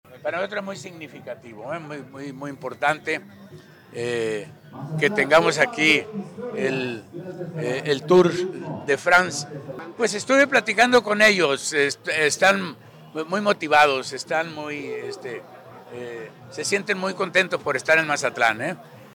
CITA-1-AUDIO-GOBERNADOR-RRM-L_ETAPE-MAZATLAN-BY-TOUR-DE-FRACE-2025.mp3